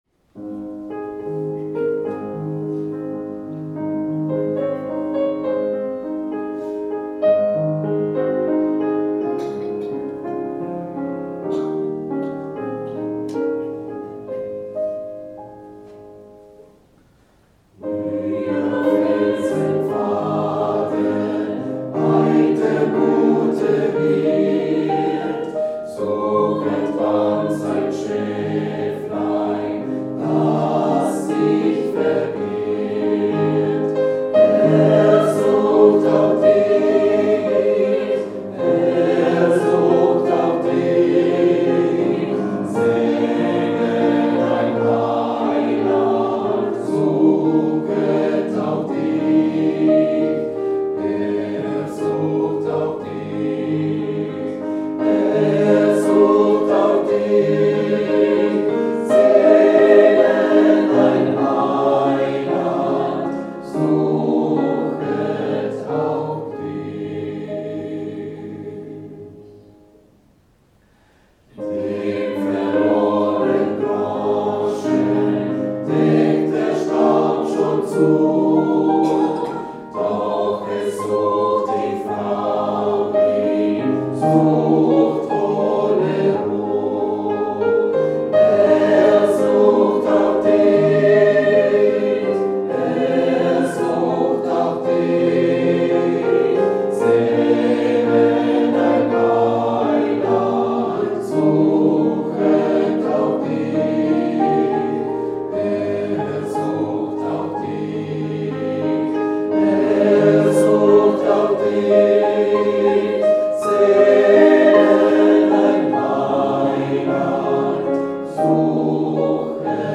Chor